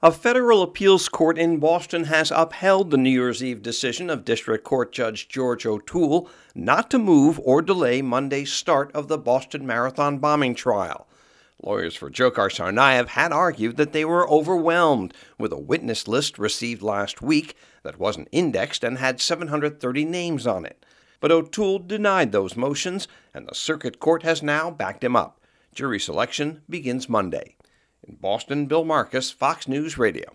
REPORTS FROM BOSTON.